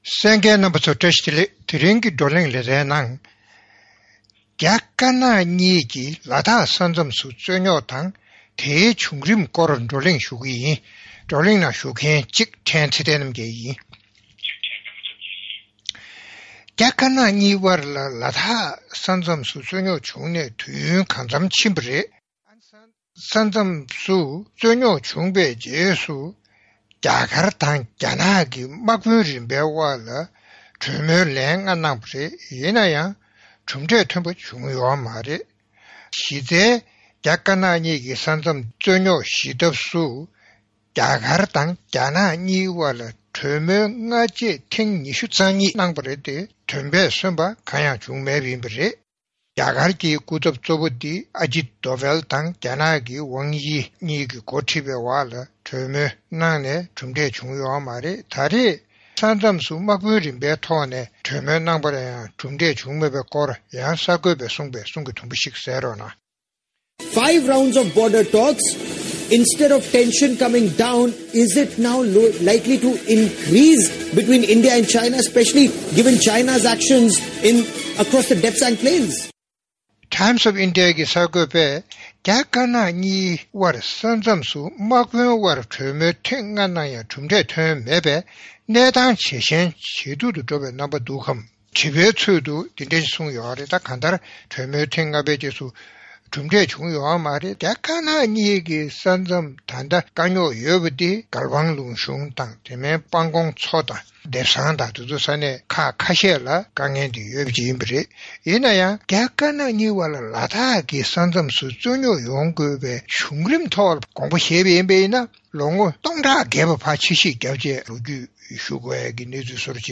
རྒྱ་དཀར་ནག་གཉིས་དབར་ལ་དྭགས་ས་མཚམས་སུ་རྩོད་རྙོག་སེལ་མ་ཐུབ་པའི་འགག་རྩ་དང་། བྱུང་རིམ་བཅས་ཀྱི་སྐོར་བགྲོ་གླེང་གནང་བ།